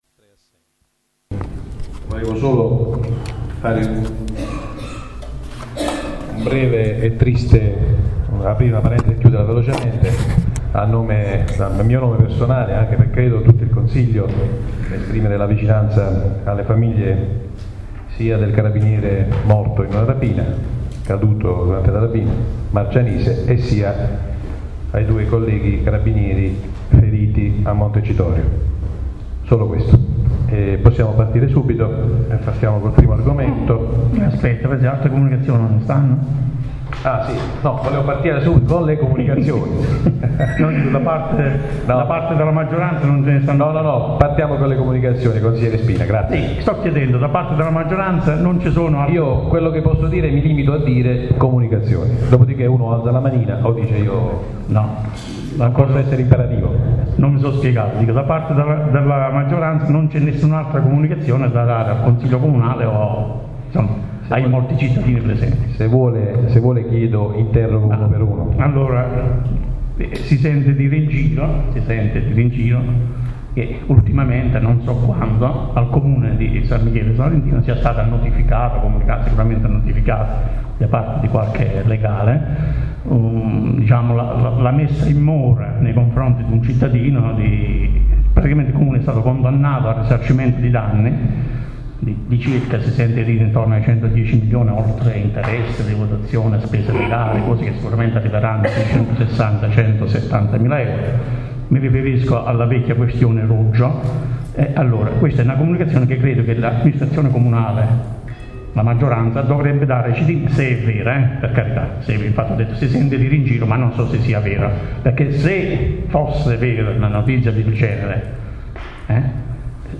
La registrazione audio del Consiglio Comunale di San Michele Salentino del 30/04/2013: